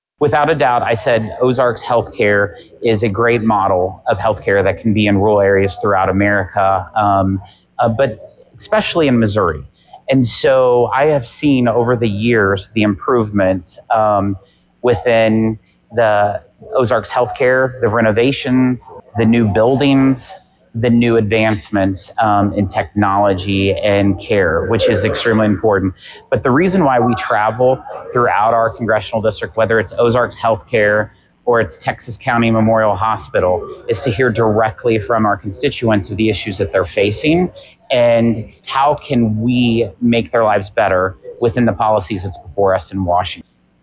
Jason Smith spoke about the meeting: